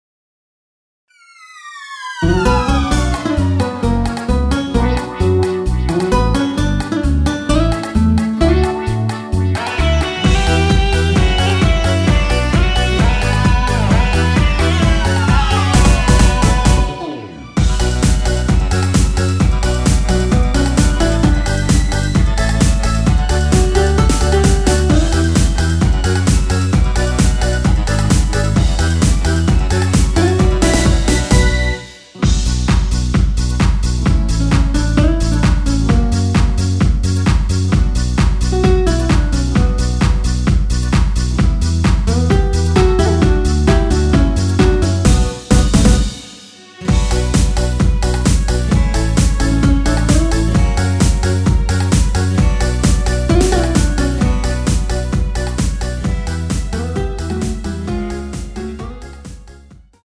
원키에서(-2)내린 MR입니다.
Bm
앞부분30초, 뒷부분30초씩 편집해서 올려 드리고 있습니다.
중간에 음이 끈어지고 다시 나오는 이유는